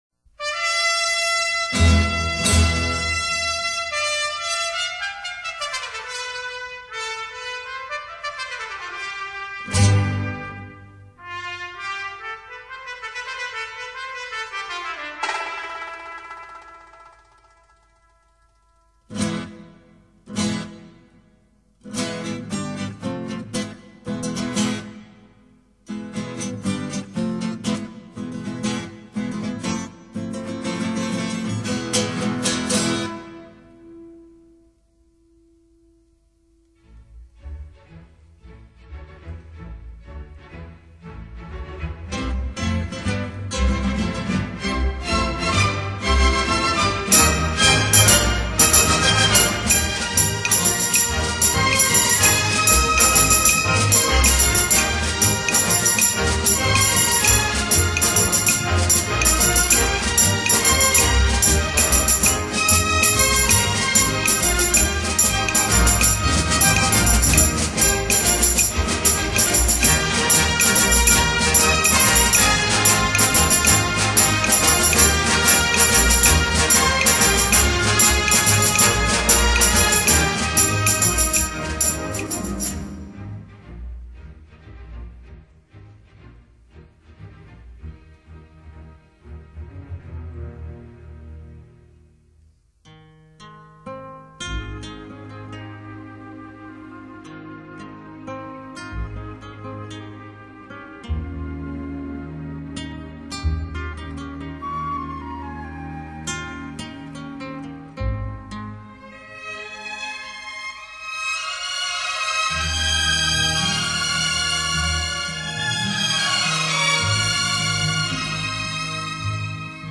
弦乐滑如丝、软如棉，管乐光辉璀璨，能制造出浩瀚壮阔兼浪漫无比的大场面。